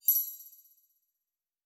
Magic Chimes 05.wav